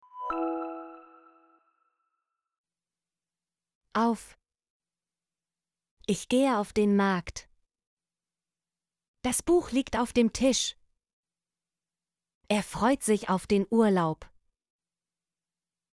auf - Example Sentences & Pronunciation, German Frequency List